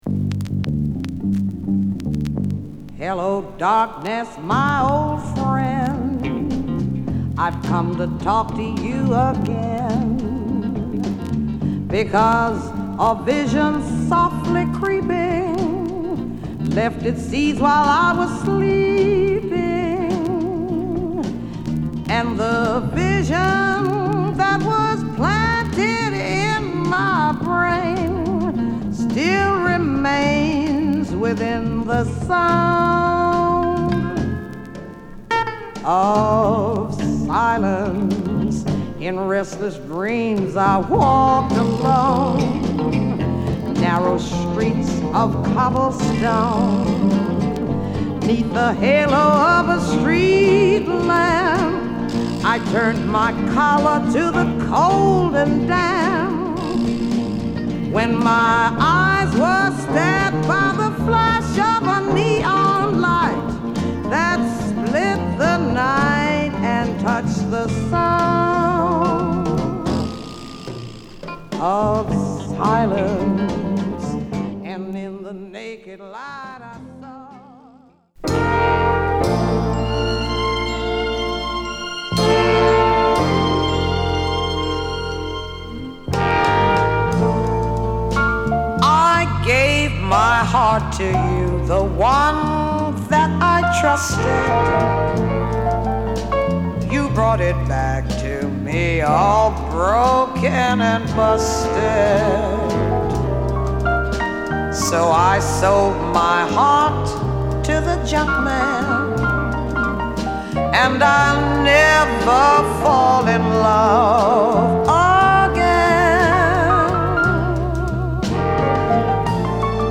＊チリパチノイズ出ます。